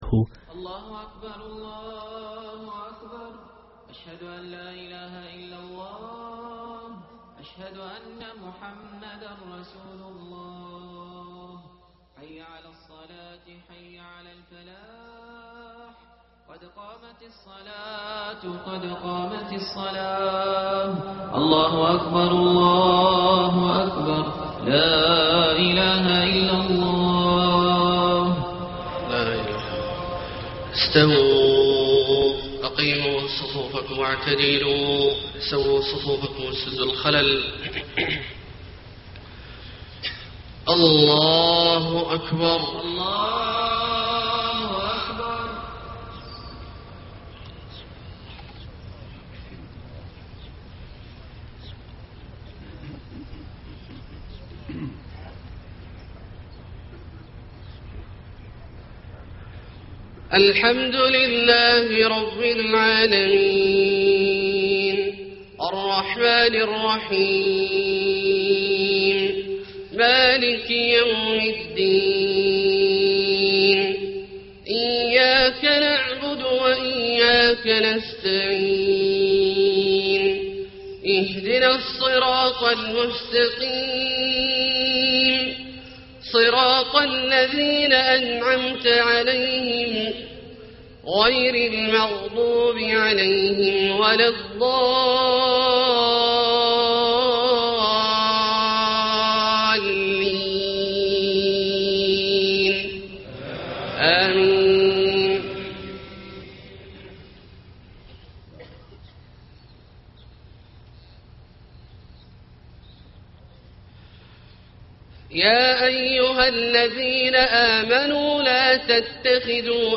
صلاة الفجر 4-6-1434 من سورة التوبة > 1434 🕋 > الفروض - تلاوات الحرمين